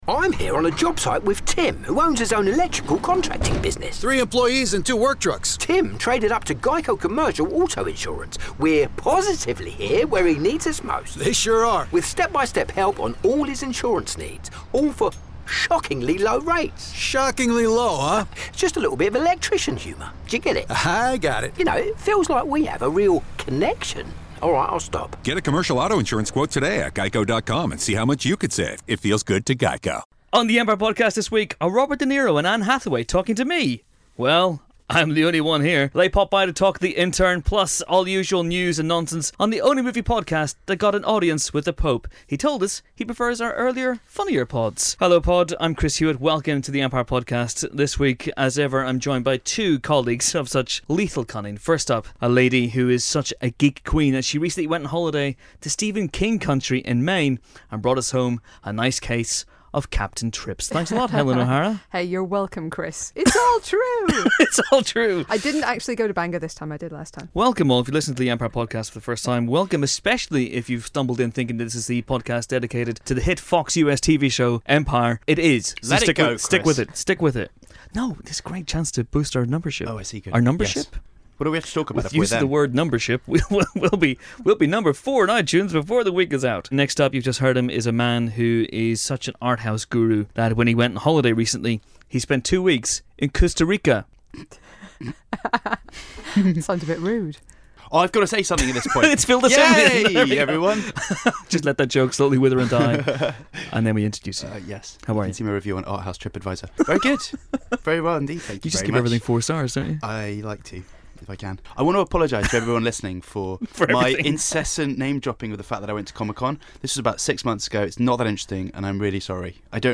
This week's Empire Podcast sees a couple of rising stars making an appearance in the audio booth of destiny. Anne Hathaway and Robert De Niro are combining for the first time in The Intern and the pair swung by to chat about their collaboration, make the tea and help with the mail-out.